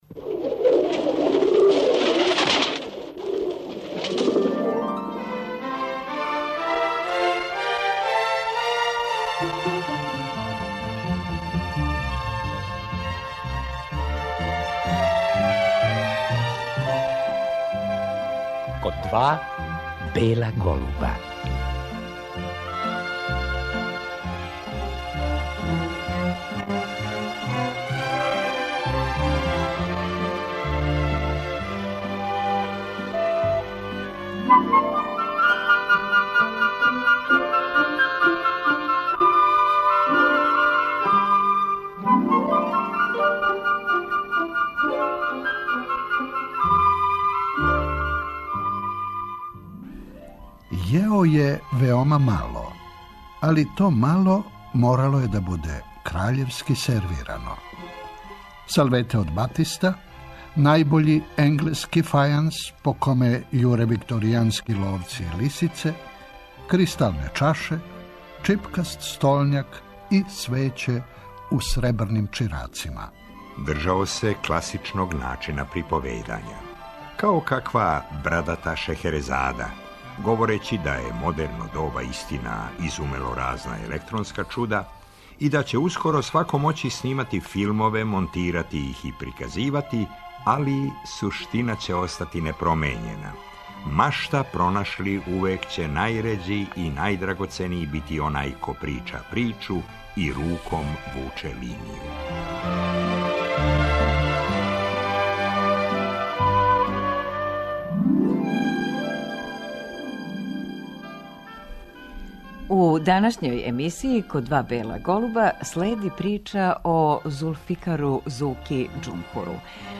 Зуко Џумхур је волео да прича о старом Београду. Казивања о Дорћолу, Фишеклији, Батали-џамији, Кнез Михаиловој улици... забележена су 1984. године.